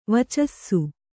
♪ vacassu